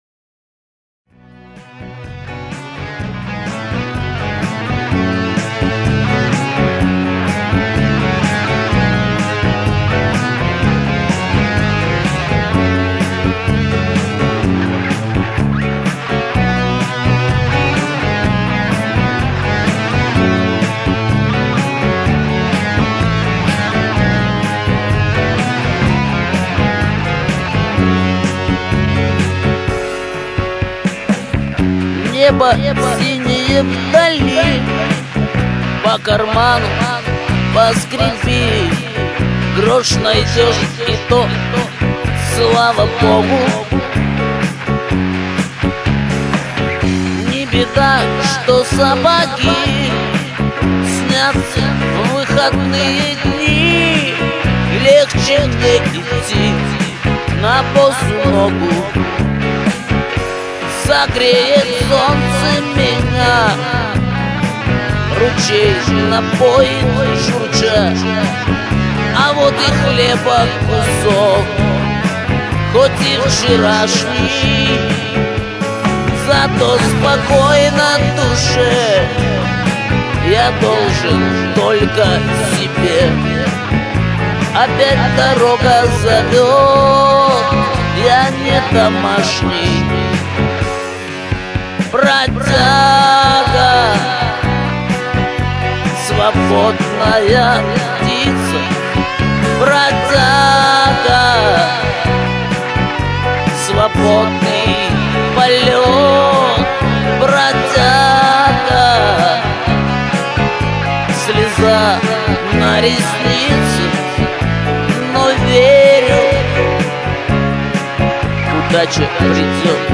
...всё это Rock"n"Roll......и не только...
Демоальбом - г.Нерехта